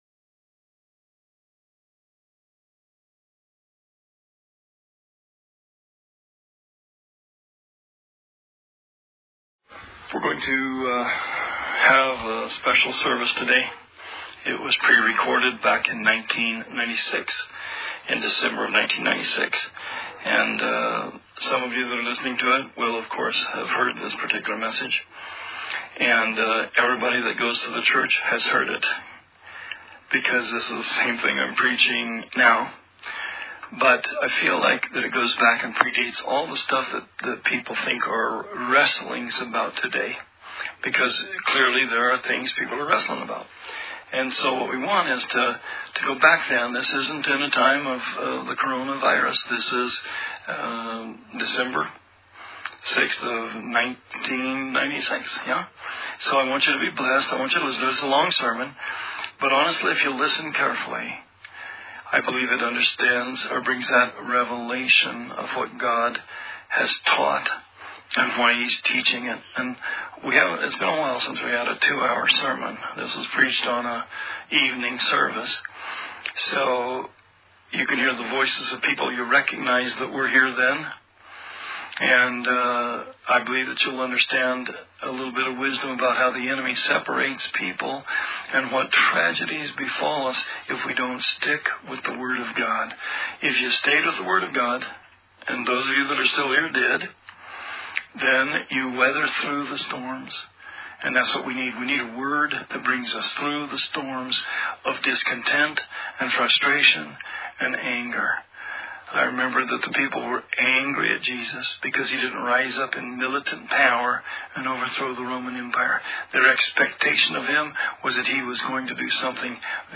Sermon 4/5/20 – RR Archives